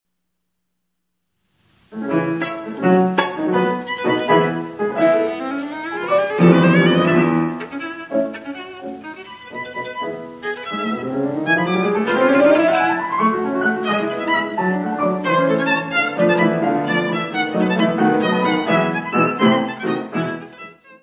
: mono; 12 cm
Music Category/Genre:  Classical Music
Allegro vivace.